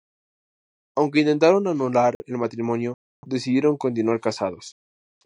Read more Adj Noun Verb Frequency C2 Hyphenated as a‧nu‧lar Pronounced as (IPA) /anuˈlaɾ/ Etymology Borrowed from Latin ānulāris Borrowed from Latin annulo In summary Borrowed from Latin ānulāris.